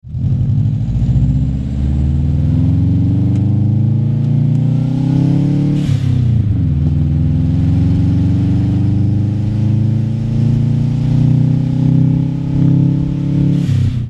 Car Info: Blue 2004 WRX
Sounds great with lots of bace!